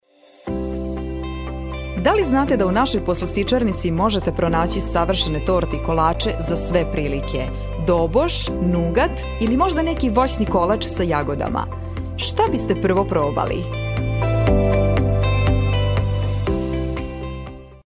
塞尔维亚语样音试听下载
塞尔维亚语配音员（女）   塞尔维亚语配音员（男1）